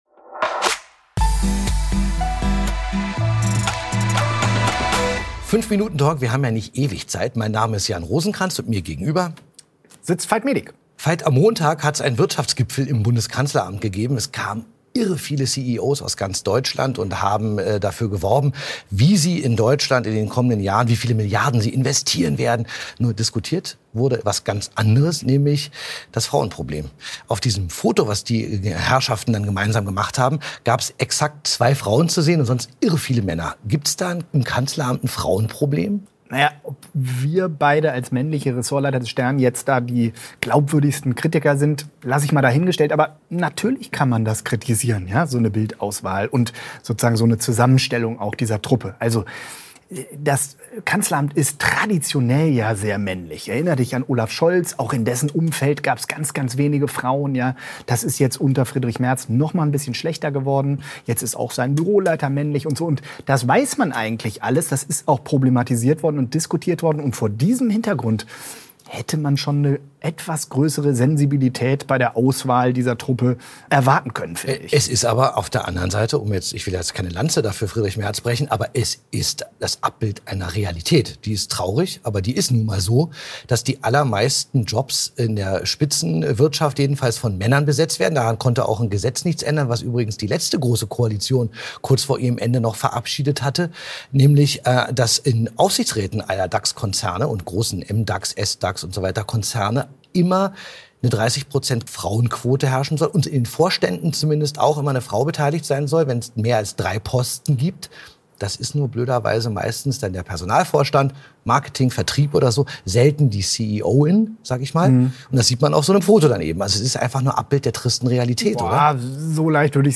5-Minuten-Talk – wir haben ja nicht ewig Zeit